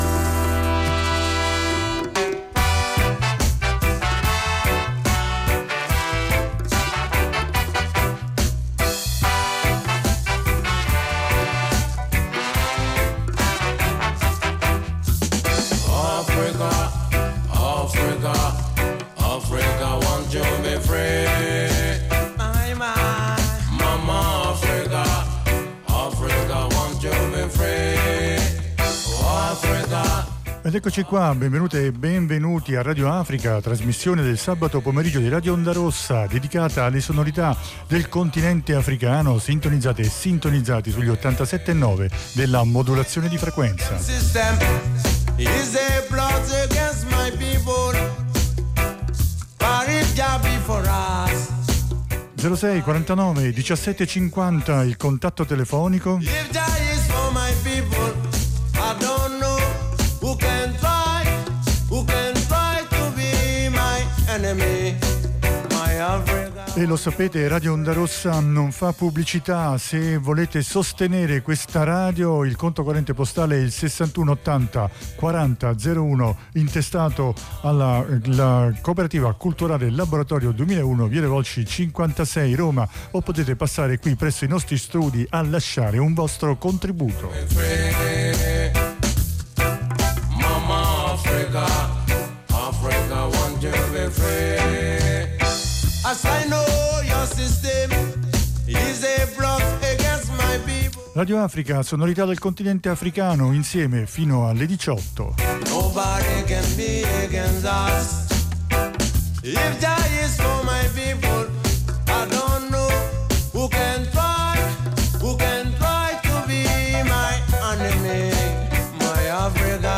Musica africana | Radio Onda Rossa